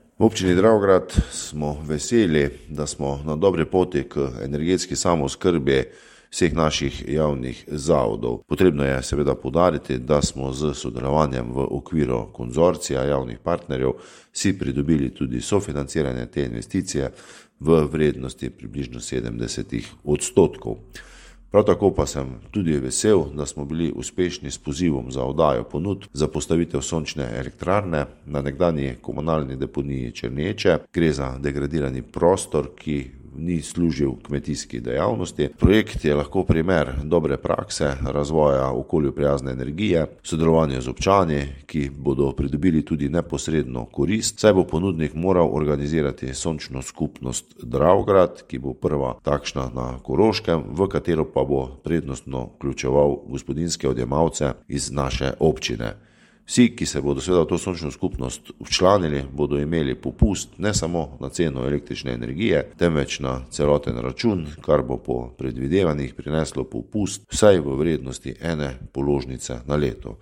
Župan Občine Dravograd Anton Preksavec je sporočil, da so pridobili ponudnika za izgradnjo sončne elektrarne do konca leta 2026 na površini veliki okoli 1,6 hektarja ter letne planirane proizvodnje 830MWh.
IZJAVA PREKSAVEC.mp3